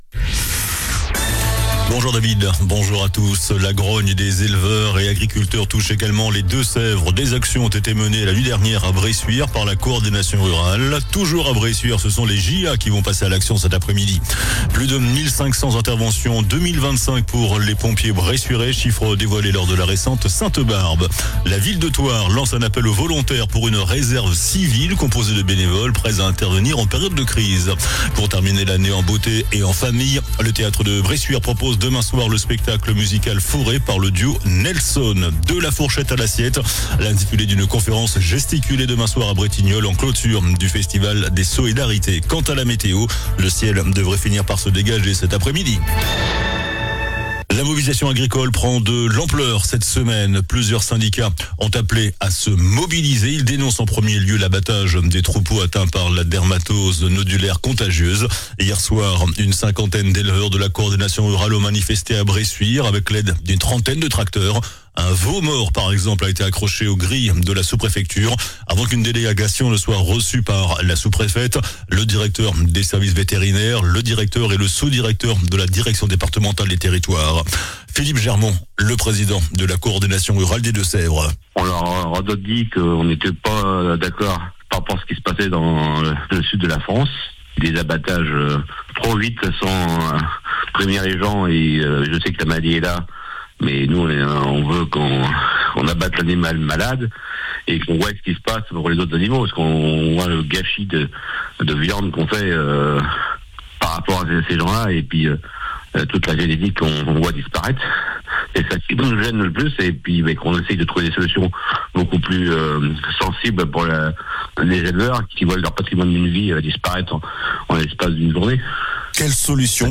JOURNAL DU MARDI 16 DECEMBRE ( MIDI )